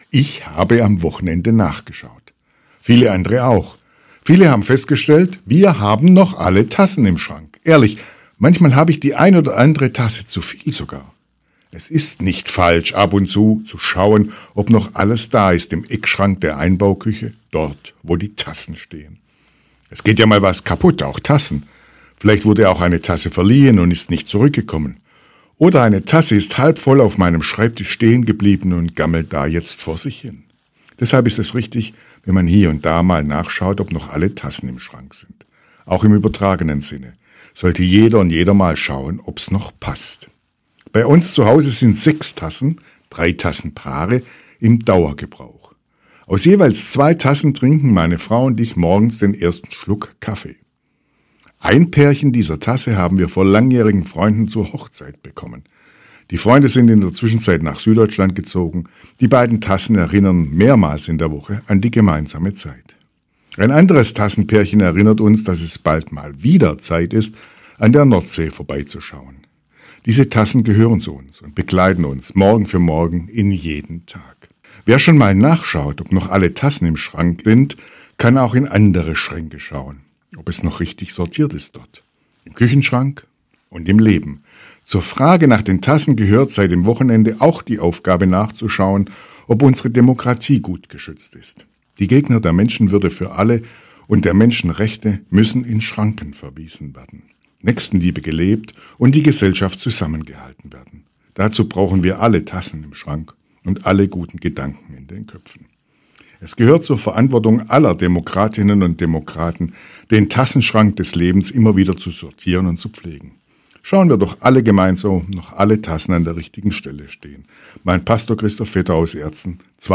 Radioandacht vom 25. Februar